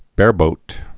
(bârbōt)